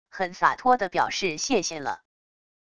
很洒脱的表示谢谢了wav音频